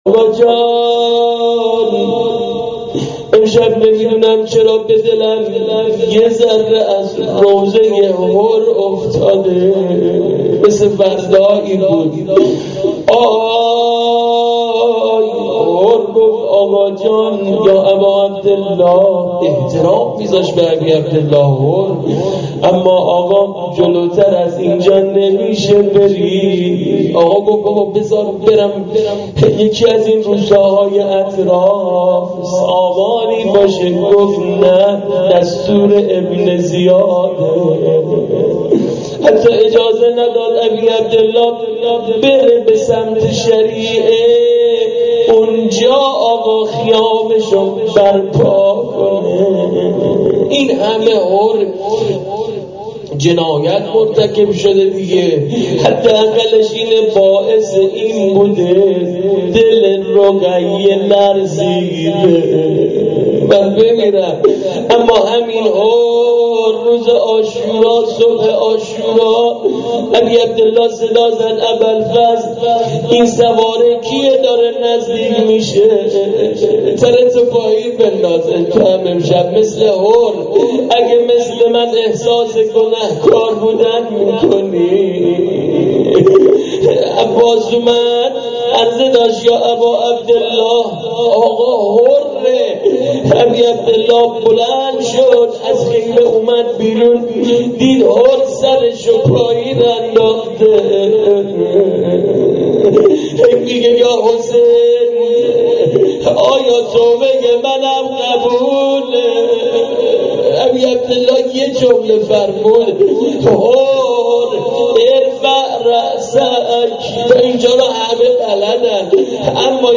روضه حر روضه حضرت رقیه.MP3
روضه-حر-روضه-حضرت-رقیه.mp3